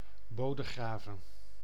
kiejtése) város és alapfokú közigazgatási egység, azaz község Hollandia nyugati részén, Dél-Holland tartományban található.